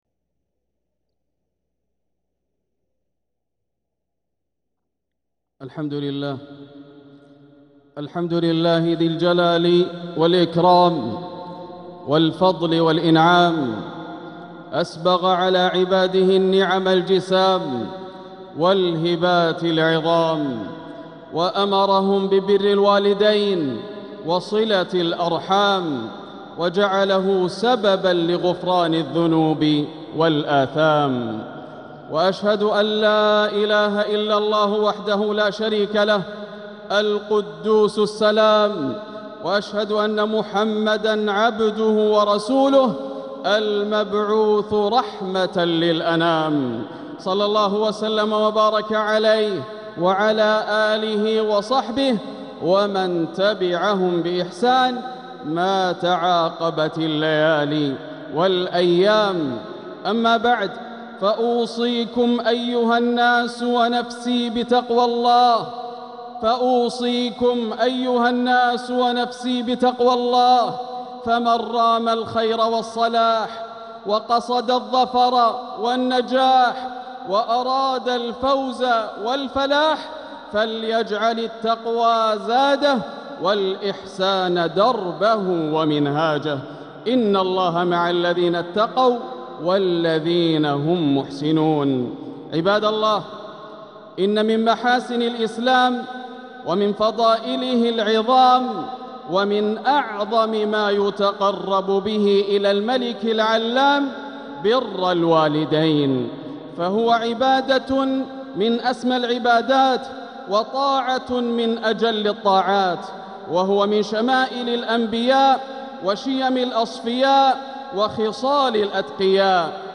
خطبة الجمعة 23 جمادى الأولى 1447هـ بعنوان ﴿وَبِالوالِدَينِ إِحسانًا) > خطب الشيخ ياسر الدوسري من الحرم المكي > المزيد - تلاوات ياسر الدوسري